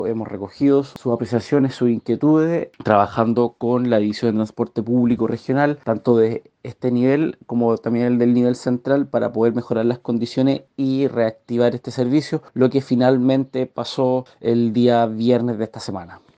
El seremi de transporte y telecomunicaciones, Jean Ugarte, dijo que se trató de un trabajo que se abordó a nivel central del ministerio para lograr un mejor servicio.